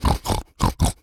pig_sniff_deep_03.wav